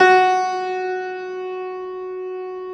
53c-pno12-F2.wav